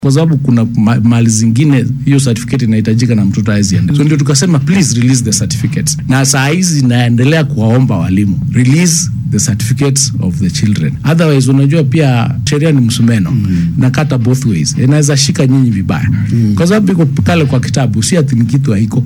DHEGEYSO:Wasiirka tacliinta oo ka hadlay soo jeedin la xiriirtay maadada xisaabta oo aan la tixgelin